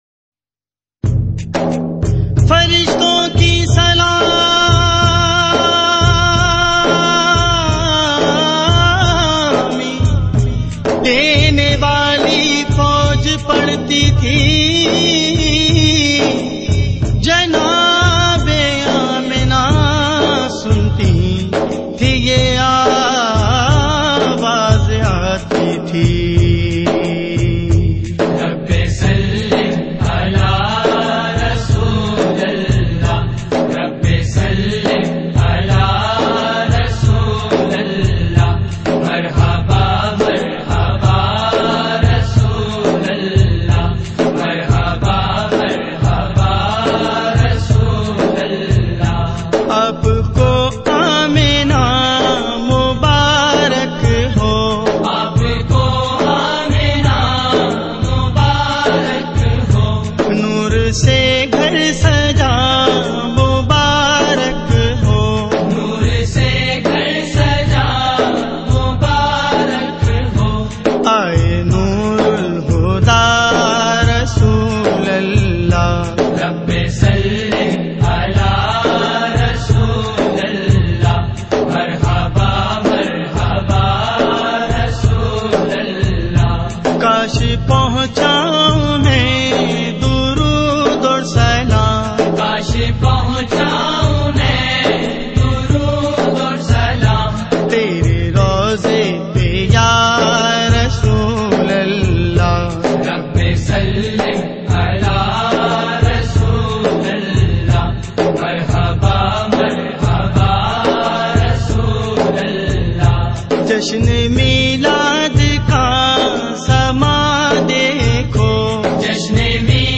Urdu Audio Naat
in a Heart-Touching Voice